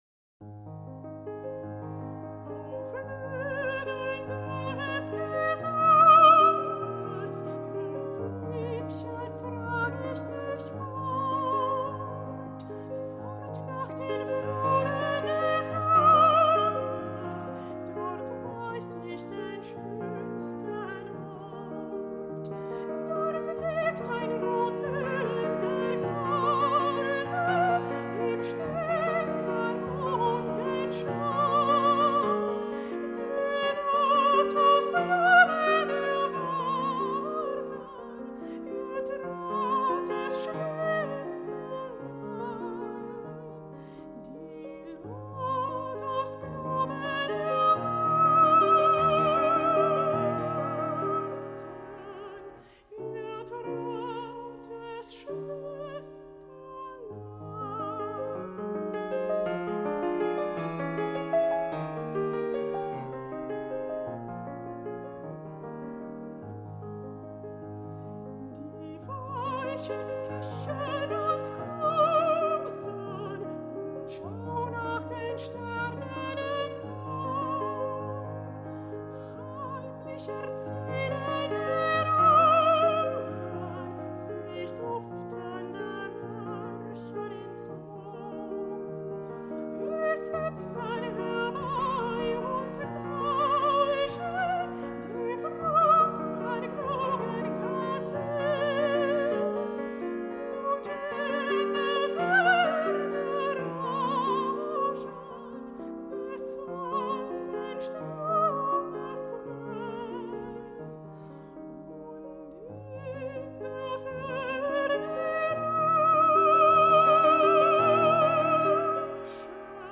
Felix Mendelssohn Bartholdy Boy Soprano: Max Emanuel Cencic Piano: Norman Shetler